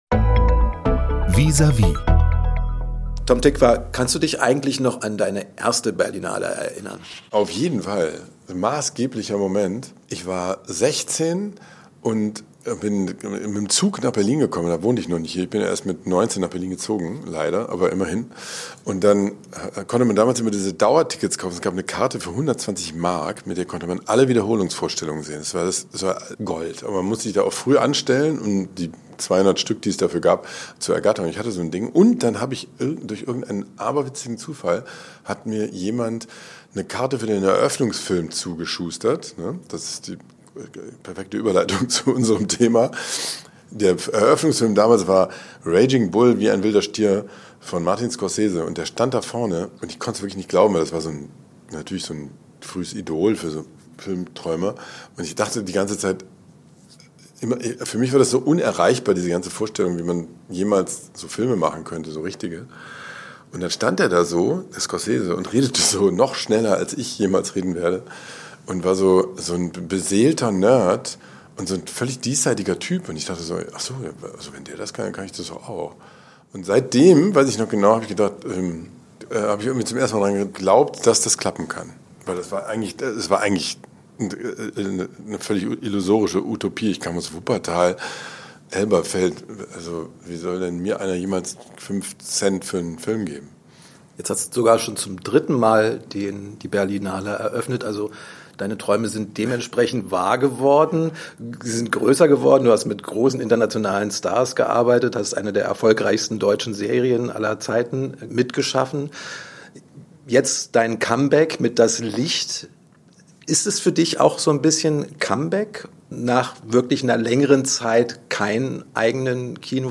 Neun Jahre hat Tom Tykwer keinen Film mehr gedreht - jetzt läuft "Das Licht" bei der Berlinale. Ein Gespräch über den Irrsinn um uns herum und das Mittel dagegen.